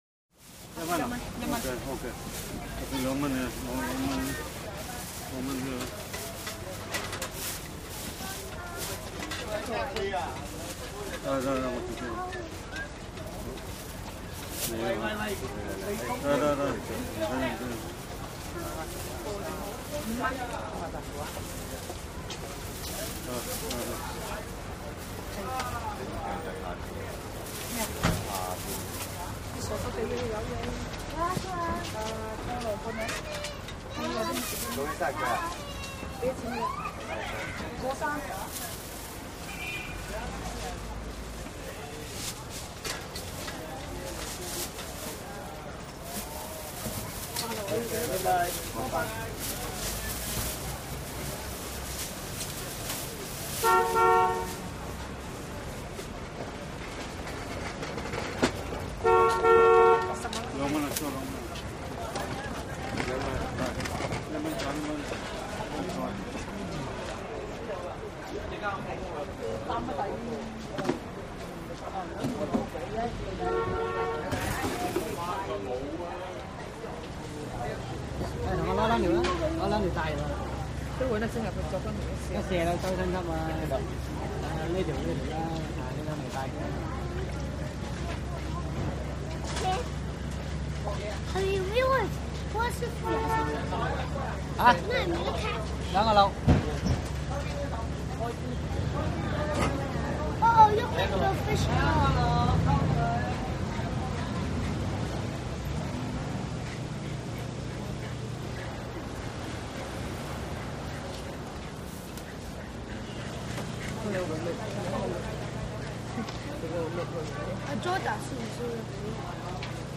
Chinatown Sidewalk Market Ambience With Close To Distant Walla And Close Plastic Bag Rustle. Cart Rattle, Butcher Chops Fish In Background.